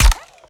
Added more sound effects.
GUNAuto_RPU1 Fire_01_SFRMS_SCIWPNS.wav